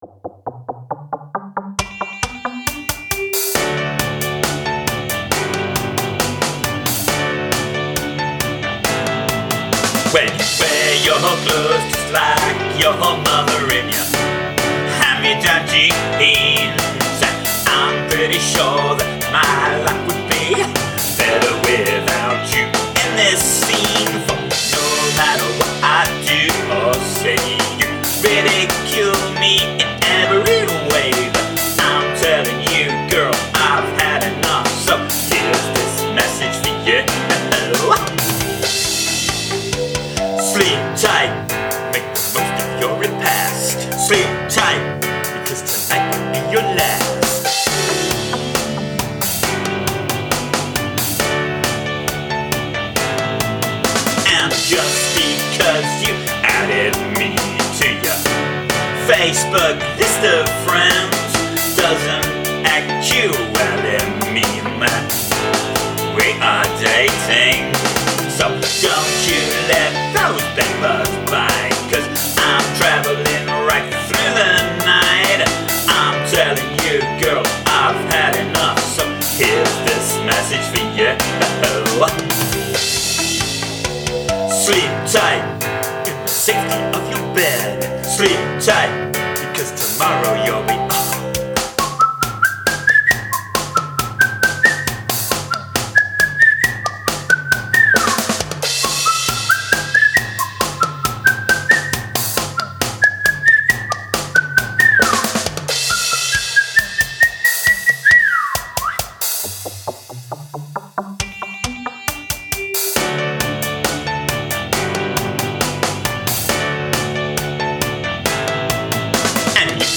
Song must include (human) whistling